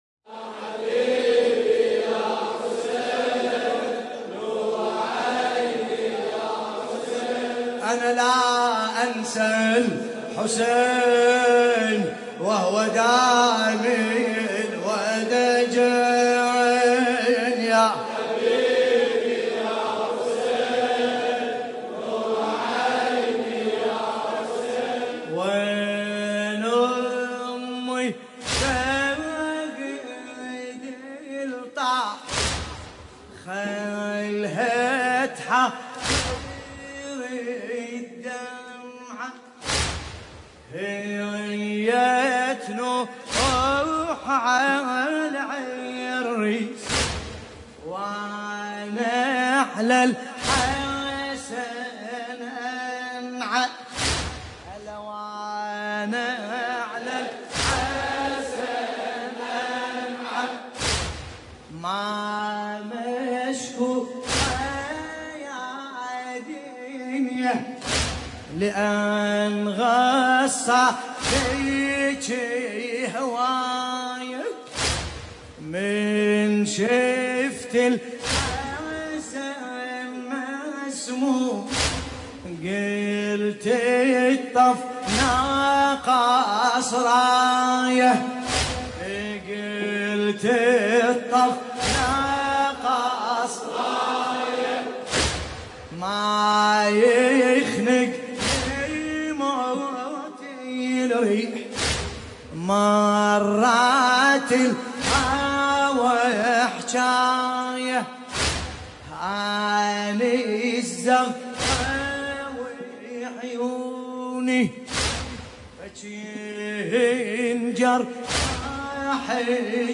ملف صوتی أم الشهيد بصوت باسم الكربلائي
الرادود : الحاج ملا باسم الكربلائيالمناسبة : شهادة الامام الحسن (ع)ليلة ٧ صفر ١٤٣٩ آل البلاغي النجف